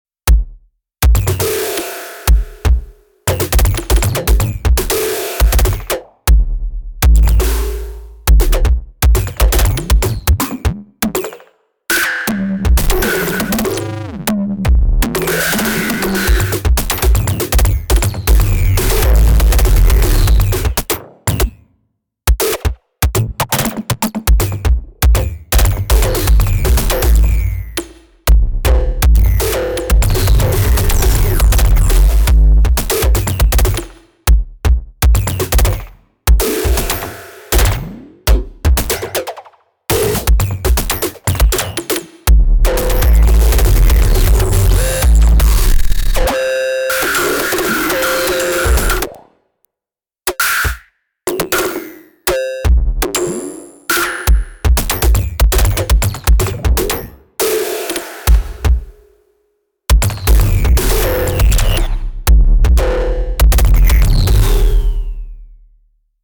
Bumping this lab for a bit because someone asked about crunchy IDM on the Syntakt and I thought SY Tone would make an excellent candidate. 3 tracks, neighbour conditions, lots of p-locking and lfo FM crunch :slight_smile: